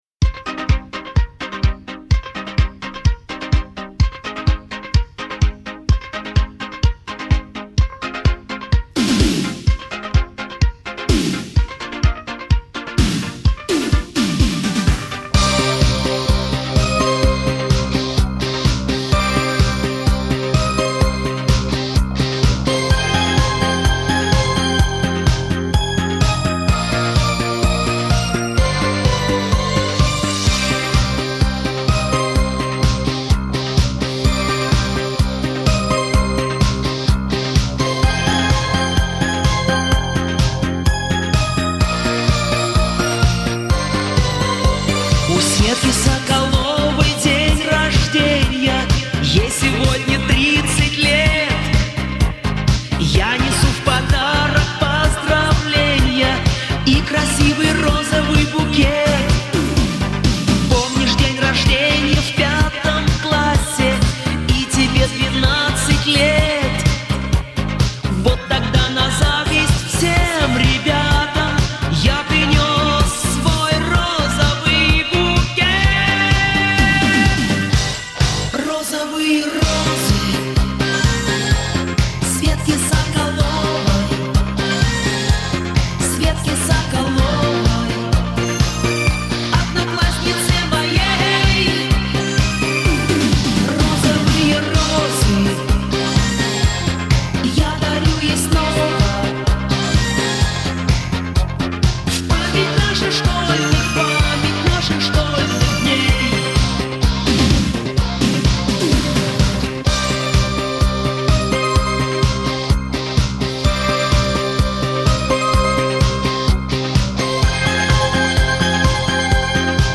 это романтичная и мелодичная песня в жанре поп